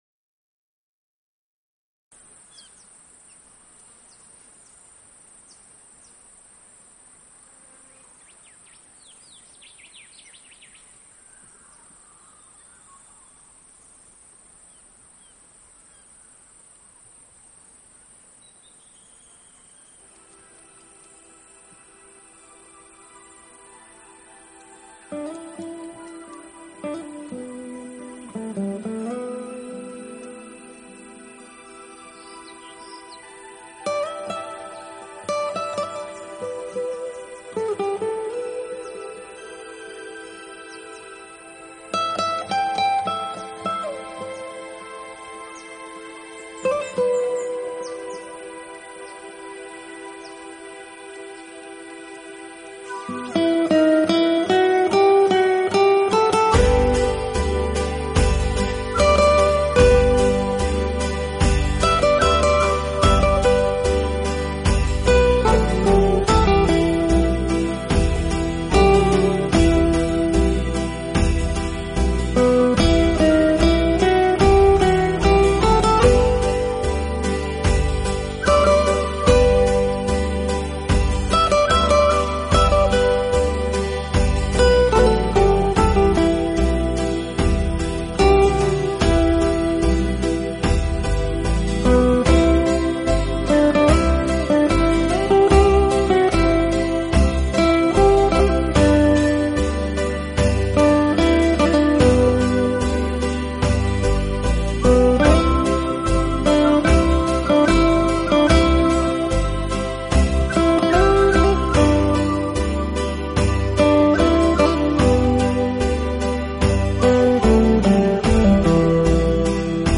【吉他专辑】
简单而清新的吉他声，Bossa Nova的慵懒节奏，
辑的曲子是绝对松驰慵懒，放下压力，最适合在午后炙阳下，饮啜一